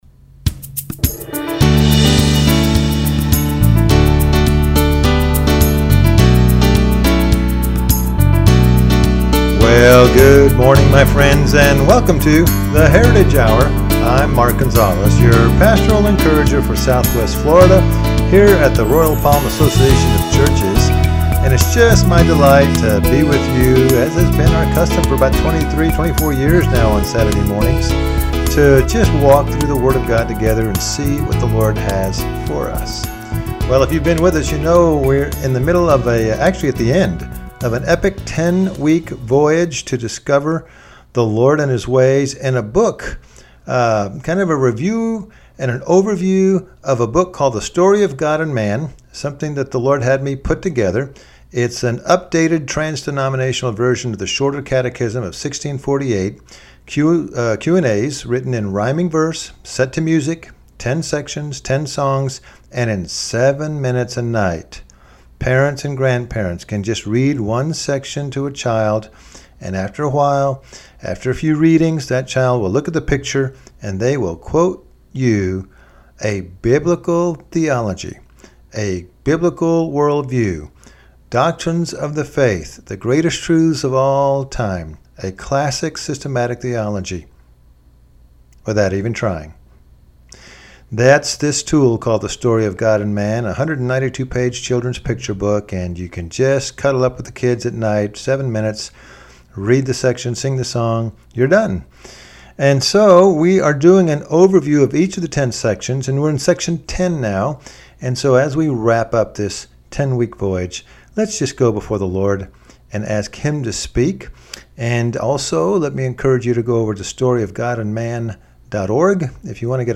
Service Type: Radio Message